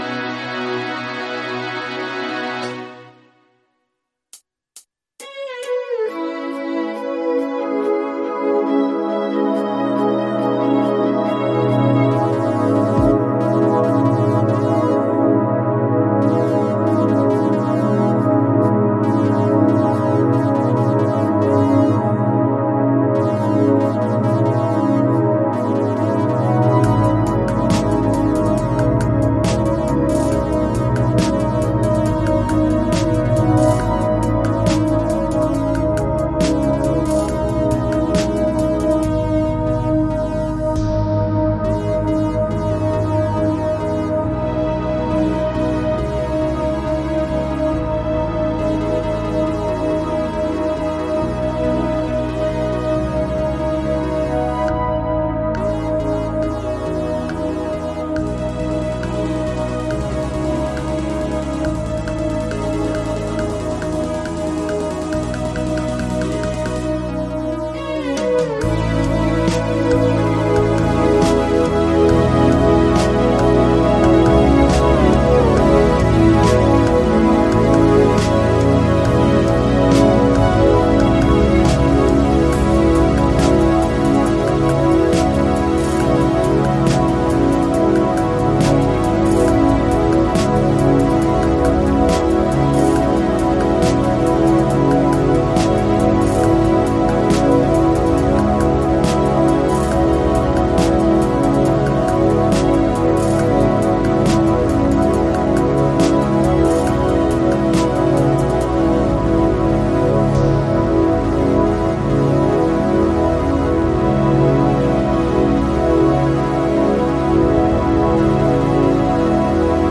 in pro MIDI Karaoke.